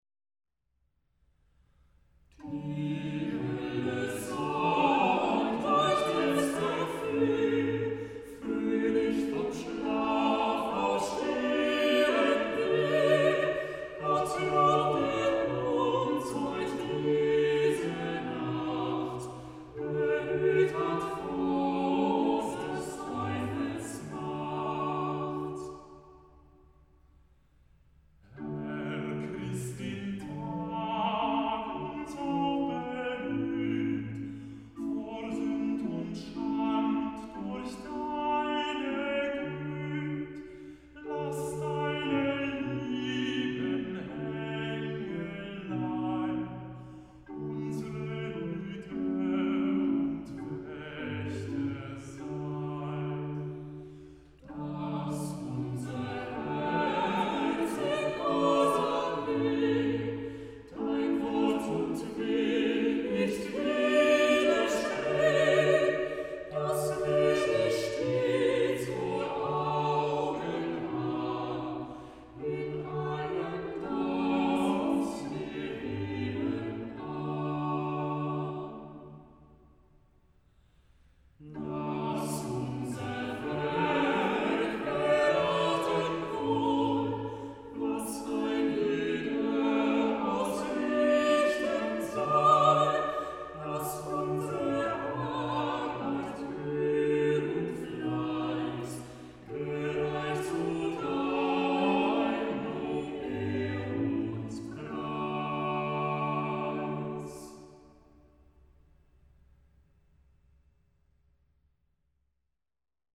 Text: Nikolaus Herman 1560 Melodie und Satz: Melchior Vulpius 1609